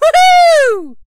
leon_lead_vo_05.ogg